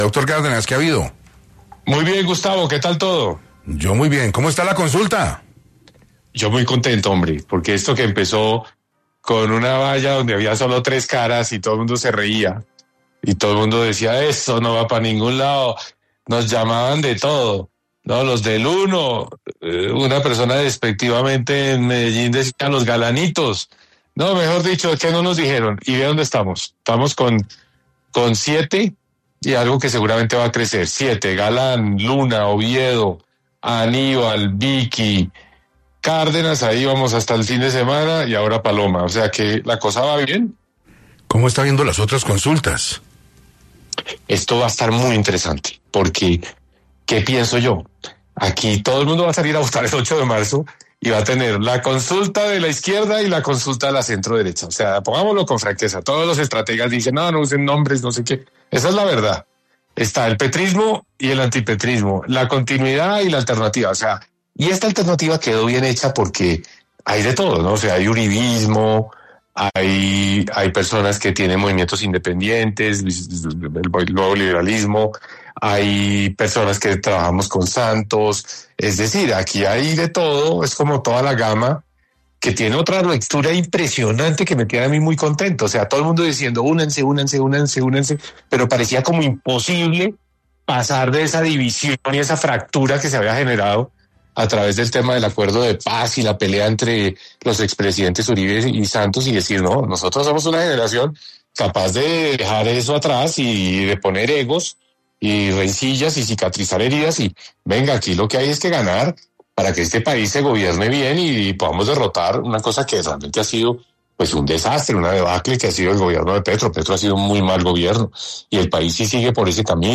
En entrevista para 6AM, el precandidato Mauricio Cárdenas se refirió al panorama de las consultas interpartidistas que se celebrarán en marzo, que contarán con la presencia de Paloma Valencia en la coalición de la centroderecha, así como al decreto de emergencia económica expedido por el gobierno.